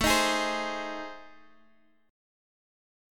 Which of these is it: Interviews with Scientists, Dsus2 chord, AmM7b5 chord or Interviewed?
AmM7b5 chord